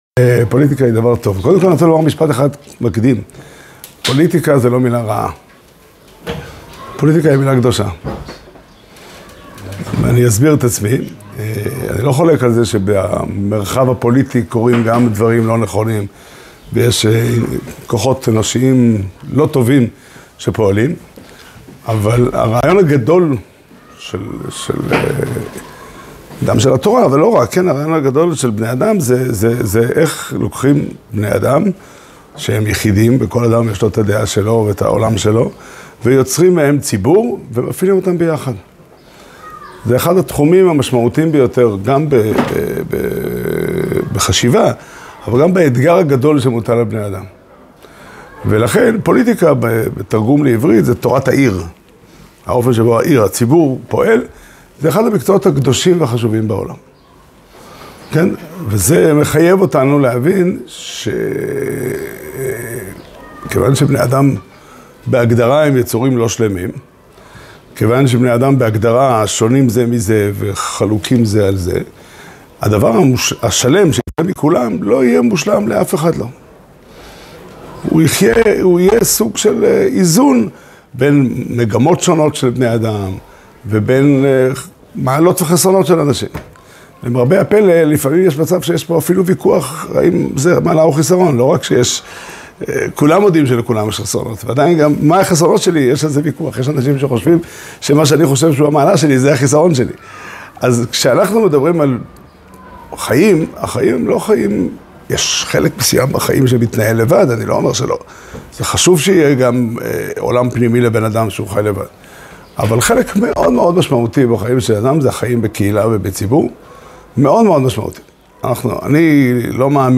שיעור שנמסר בבית המדרש פתחי עולם בתאריך ו' שבט תשפ"ה